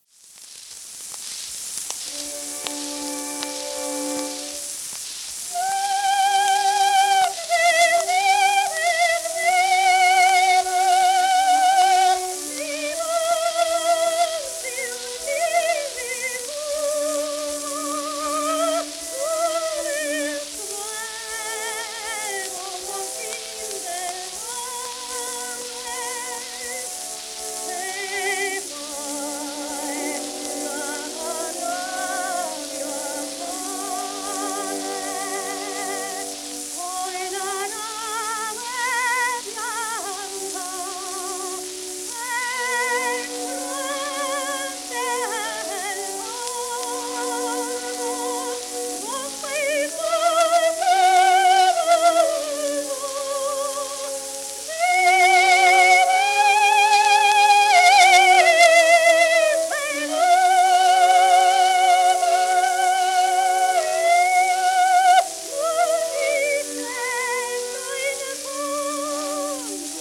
w/オーケストラ
12インチ片面盤
ラッパ吹込み（機械式録音）